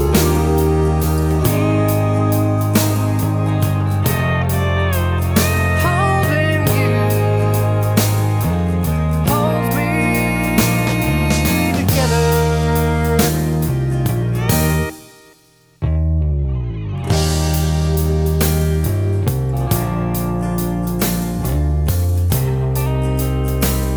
Country (Female)